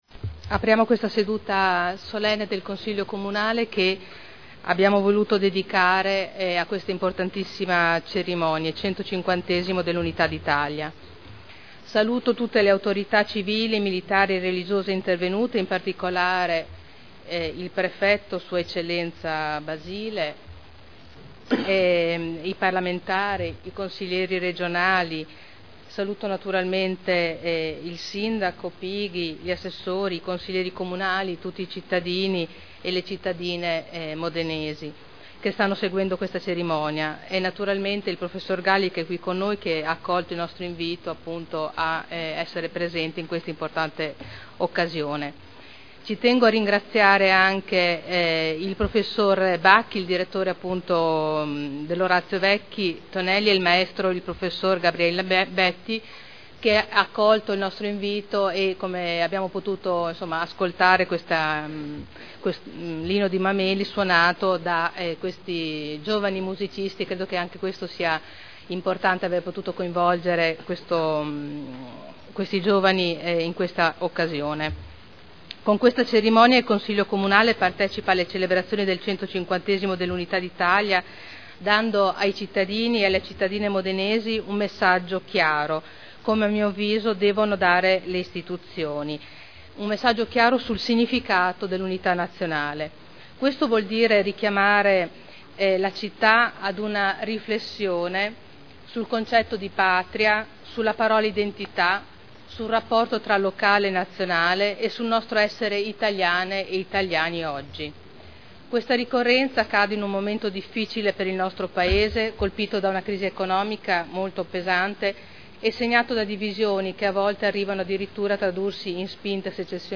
Apertura seduta solenne 150° anniversario dell'unità d'Italia. Intervento introduttivo della Presidente del Consiglio Caterina Rita Liotti